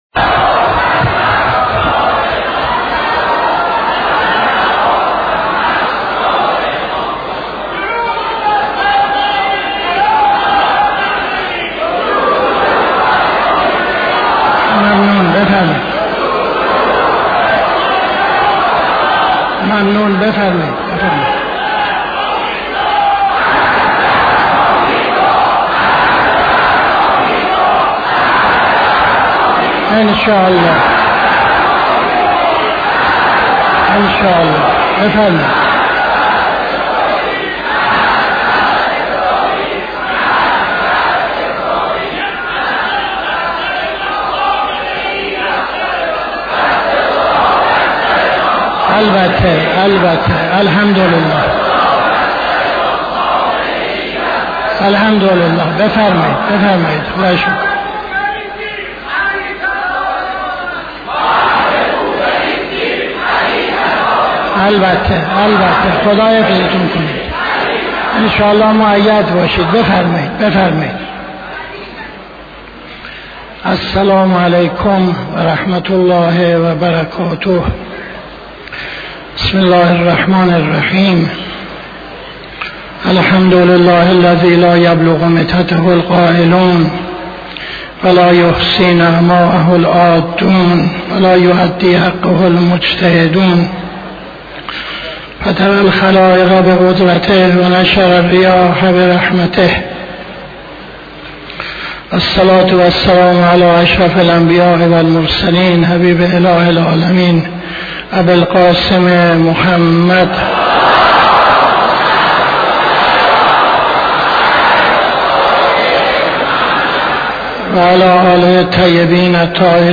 خطبه اول نماز جمعه 28-08-78